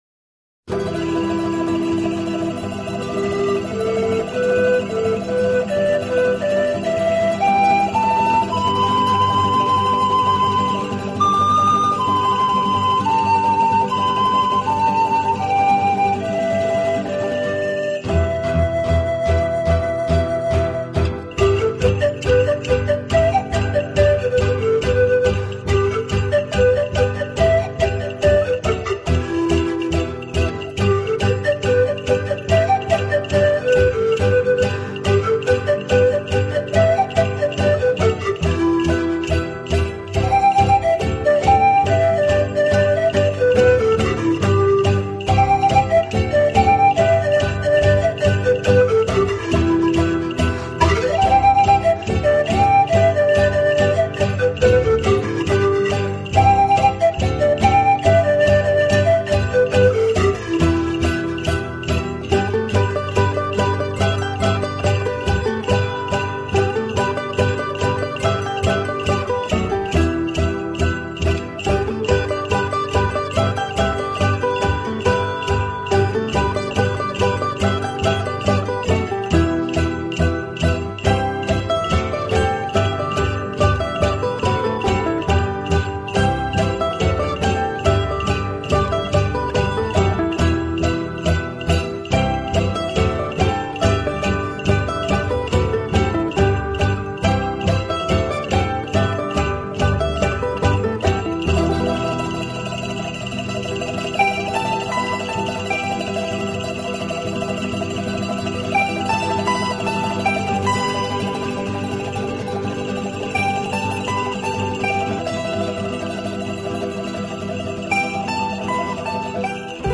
【南美排箫】